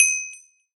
pop.ogg